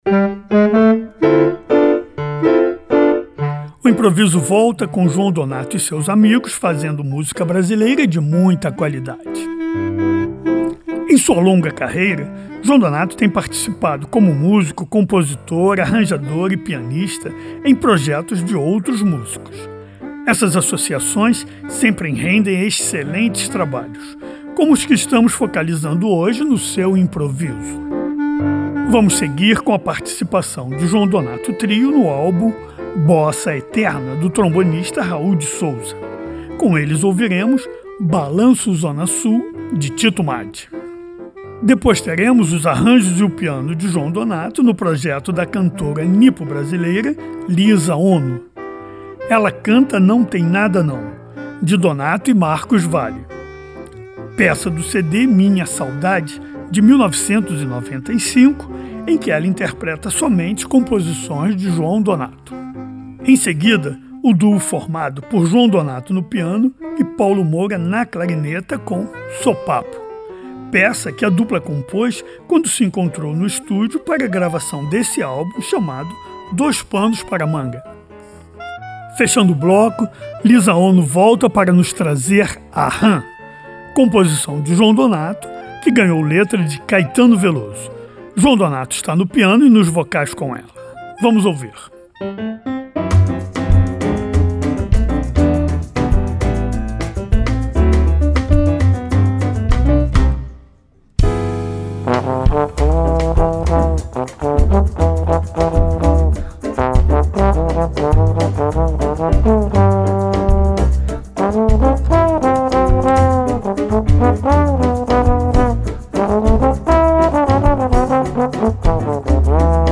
Colaborador dos primórdios do samba jazz
Música Brasileira Jazz